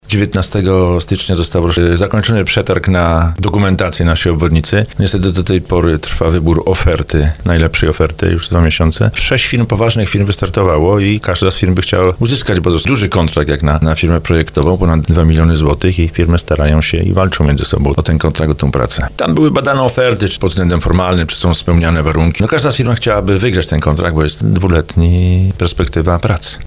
Nie jest jeszcze znana firma, która wykona projekt obwodnicy Żabna. Procedura nieco się przedłuża – mówił o tym w audycji Słowo za Słowo burmistrz Tomasz Kijowski.